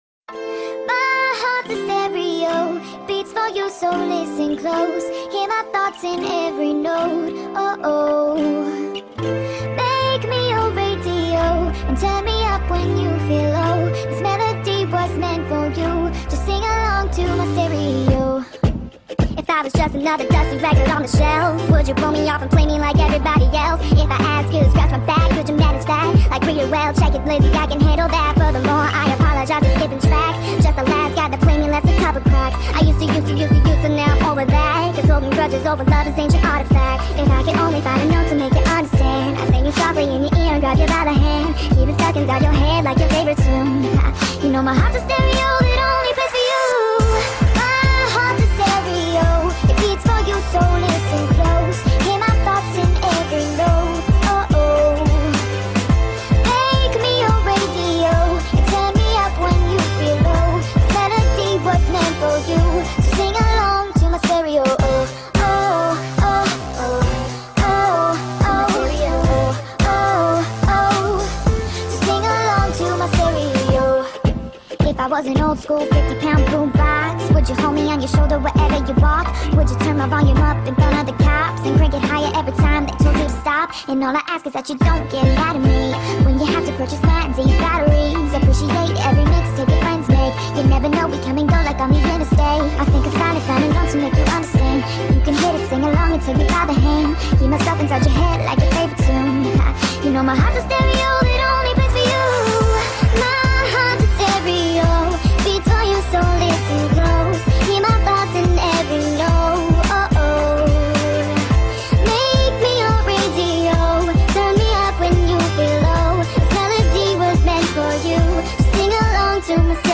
صدای دختر